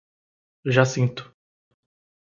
Pronunciado como (IPA) /ʒaˈsĩ.tu/